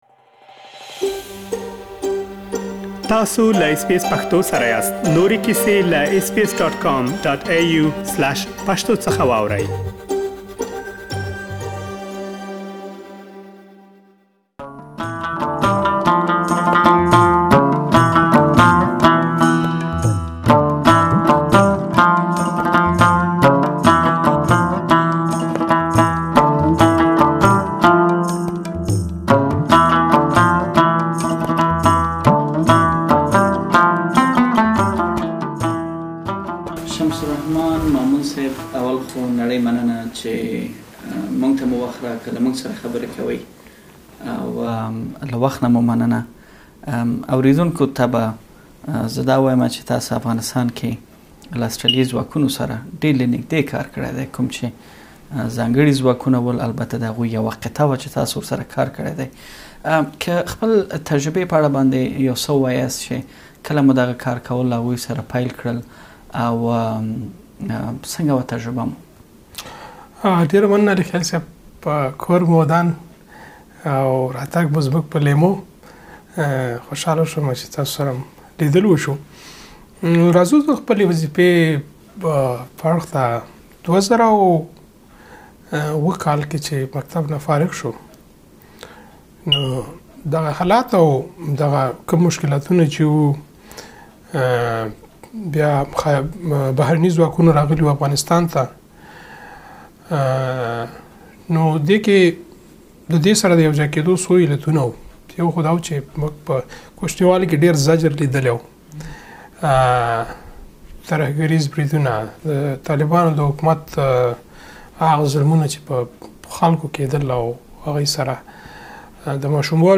د اسټراليايي ځواکونو پخواني ژباړونکي او کلتوري سلاکار له اس بي اس پښتو خپرونې سره ځانګړې مرکه کړې او خپل سترګو ليدلی حال يې شريک کړی.